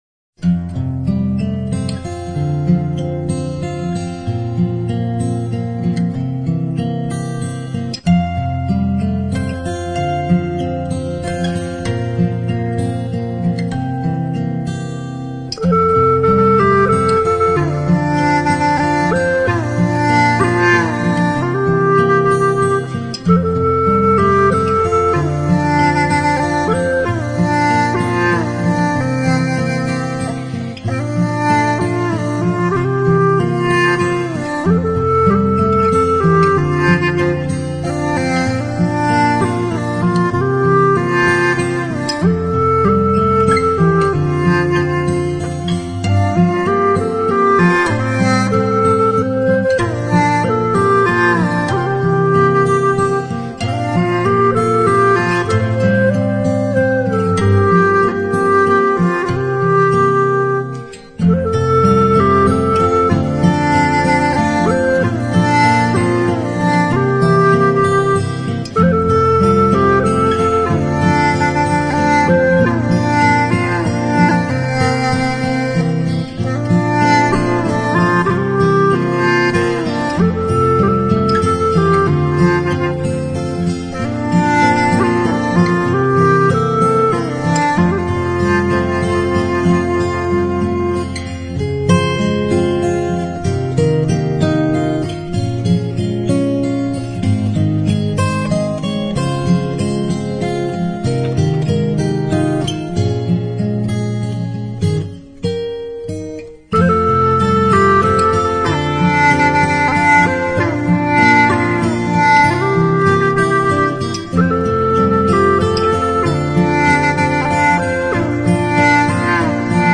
欧美经典新世纪轻音乐精选集.
HIFI音乐的音色悦耳、悠扬、细致，乐器的质感鲜明无比，仿佛伸手可触。
一点爵士的……一点点风格的融合成了不一样的HIFI音乐